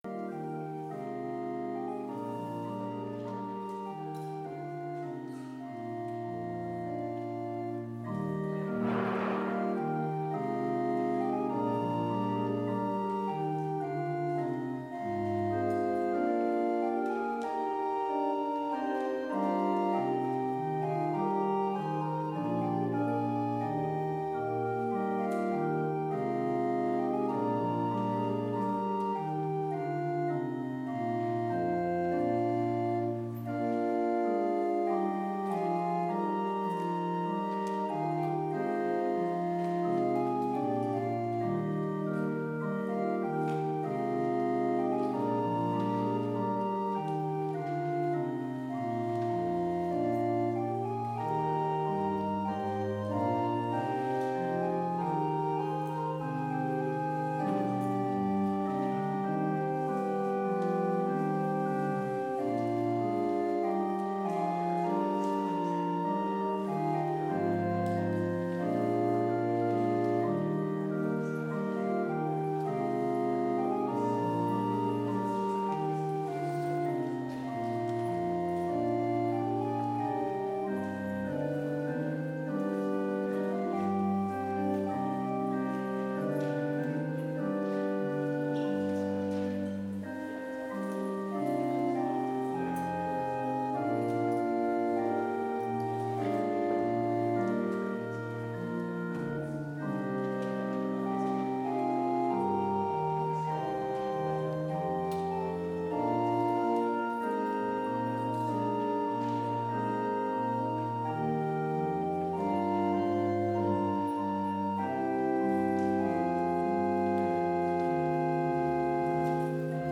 Complete service audio for Chapel - February 17, 2021